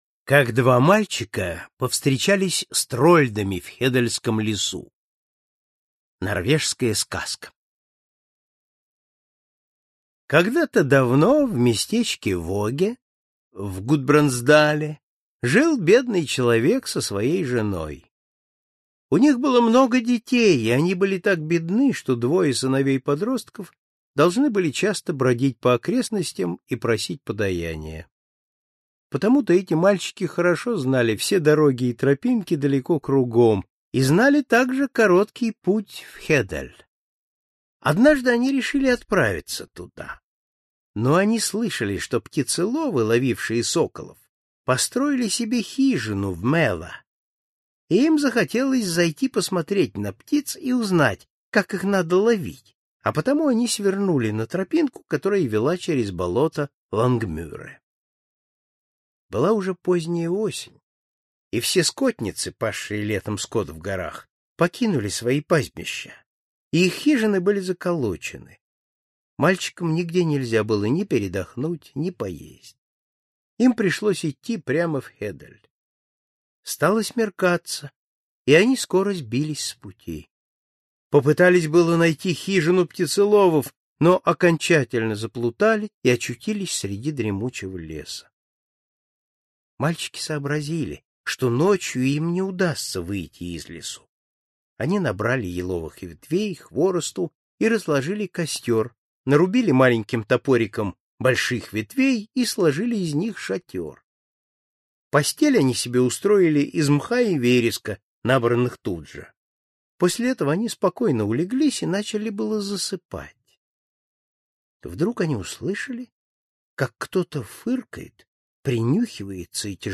Аудиокнига Золотая книга сказок. Норвежские сказки | Библиотека аудиокниг